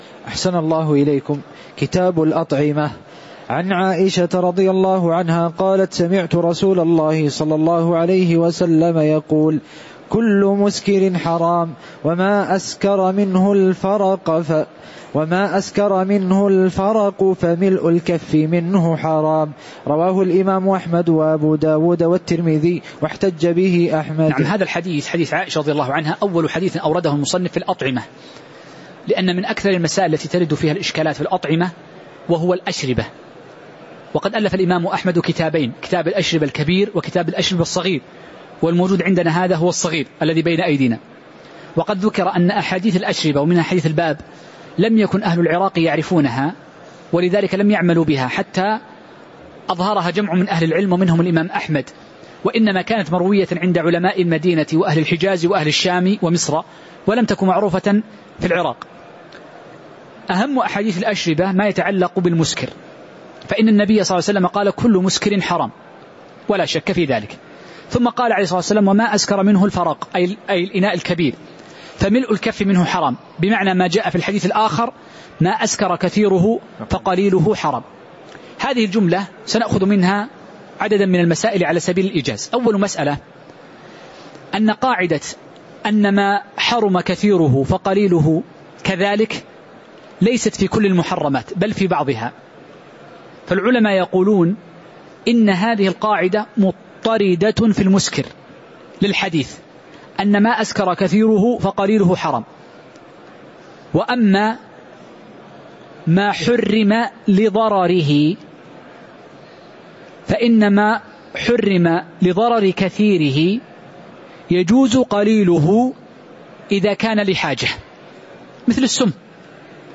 تاريخ النشر ١١ ربيع الأول ١٤٤١ هـ المكان: المسجد النبوي الشيخ